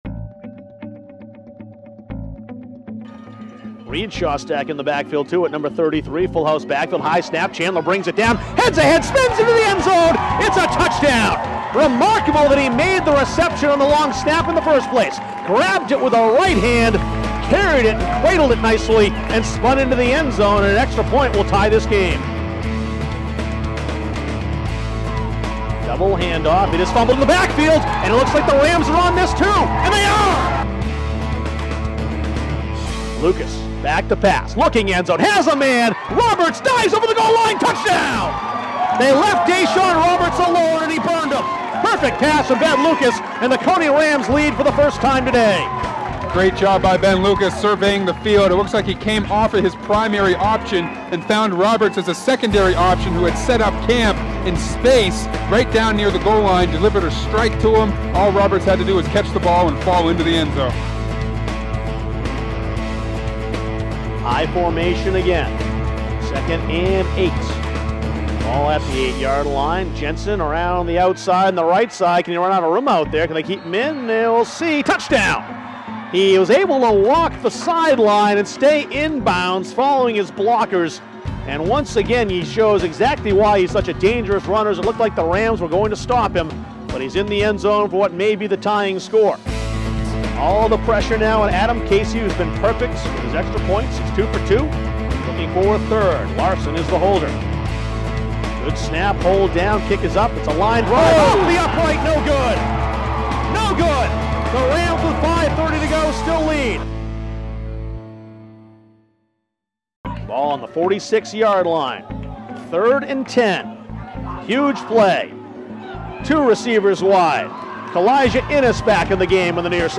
High School Football Highlights (2014)